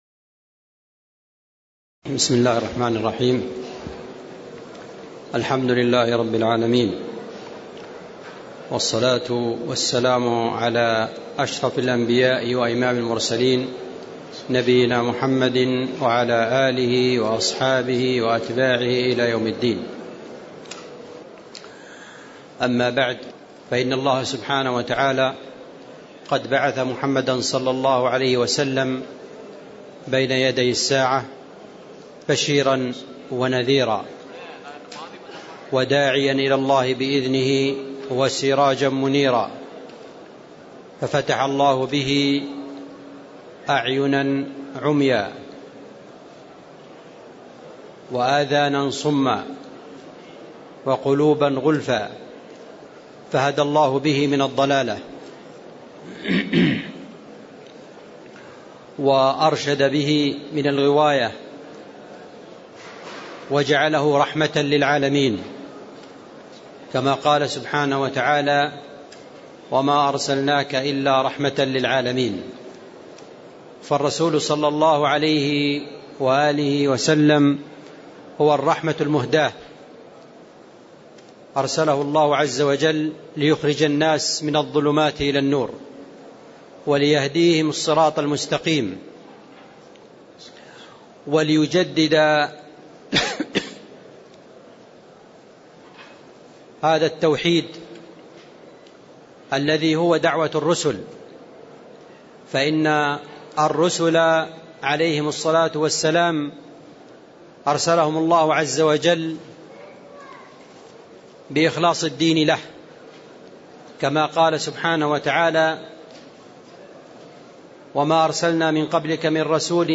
تاريخ النشر ٣٠ ربيع الثاني ١٤٣٨ هـ المكان: المسجد النبوي الشيخ